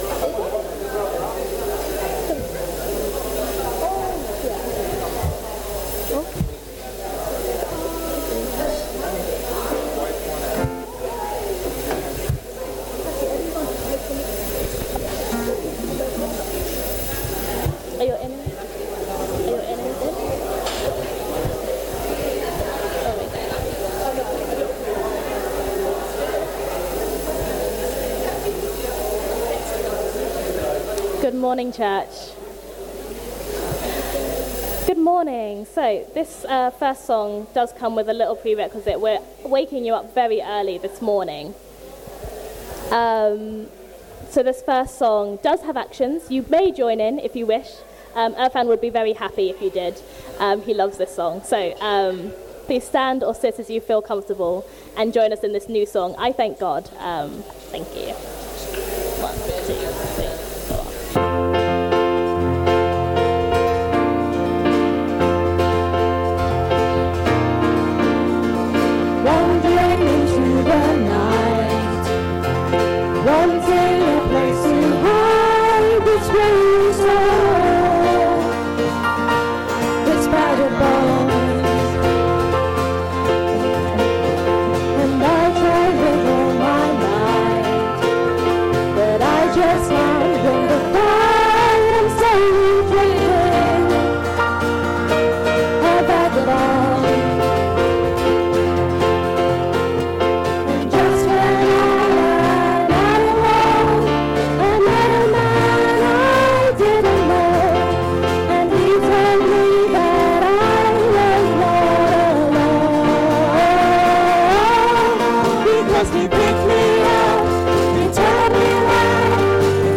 All-age service - Sittingbourne Baptist Church